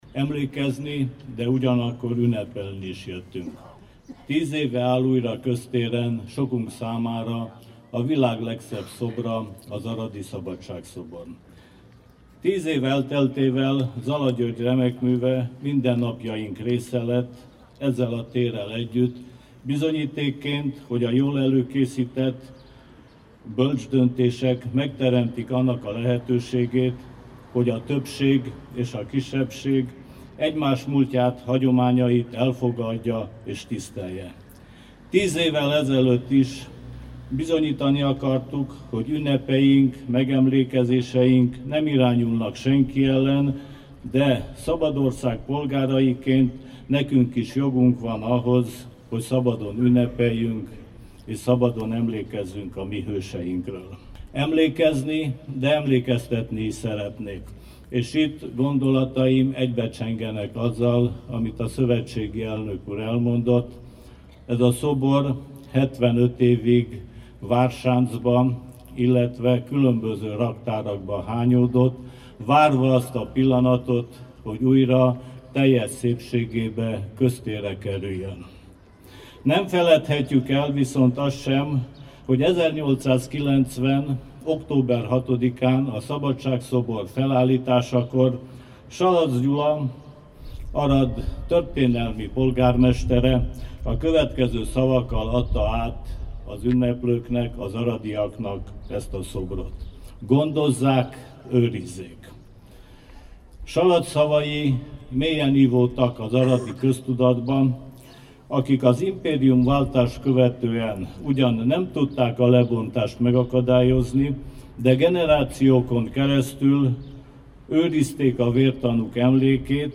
„A világ legszebb szobra az aradi Szabadság-szobor” – Király András beszéde az újraállítás 10. évfordulóján [AUDIÓ]
„Emlékezni, de ugyanakkor emlékeztetni gyűltünk itt össze” – hangsúlyozta Király András tanügyi államtitkár, az aradi Szabadság-szobor Egyesület elnöke péntek délután, Zala György allegorikus szoborcsoportja újraállításának 10. évfordulóján. Emlékeztette a hallgatóságot, hogy a magyar közösség megemlékező ünnepségei senki ellen nem irányulnak, és hogy szabad ország polgáraiként elvárjuk, hogy szabadon tisztelhessük hőseinket.
Kiraly_beszede_a_Szabadsag-szobor_10_evfordulojan.mp3